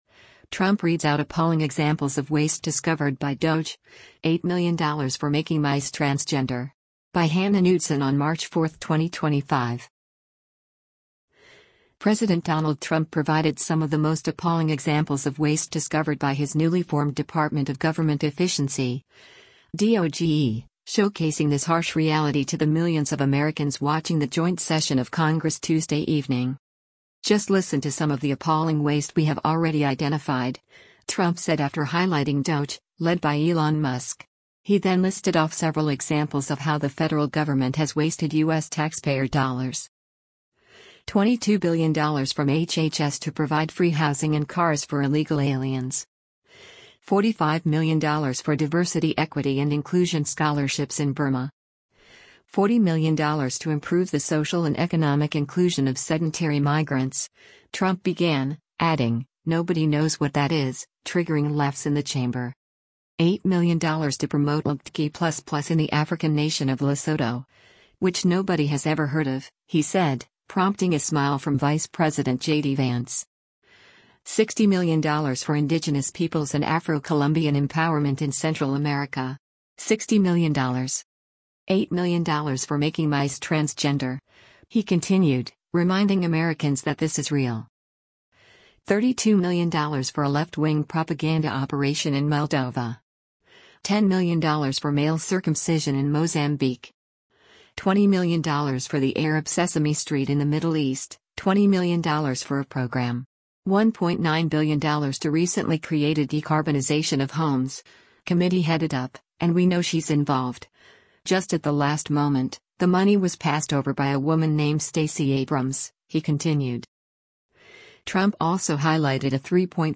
President Donald Trump provided some of the most “appalling” examples of waste discovered by his newly formed Department of Government Efficiency (DOGE), showcasing this harsh reality to the millions of Americans watching the joint session of Congress Tuesday evening.
“…$22 billion from HHS to provide free housing and cars for illegal aliens. $45 million for Diversity Equity and Inclusion scholarships in Burma. $40 million to improve the social and economic inclusion of sedentary migrants,” Trump began, adding, “Nobody knows what that is,” triggering laughs in the chamber.